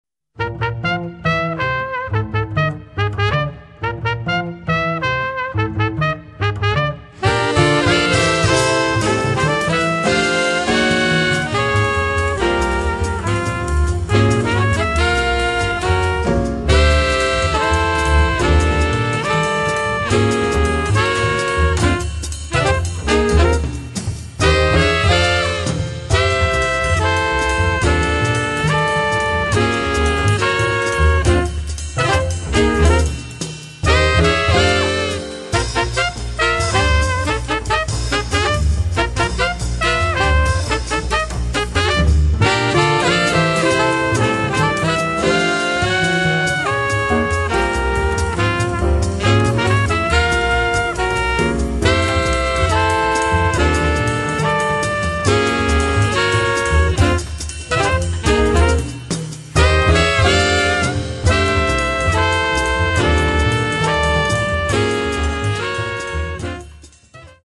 trumpet e flugelhorn
piano
drums and vibraphone